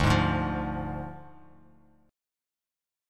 Ddim chord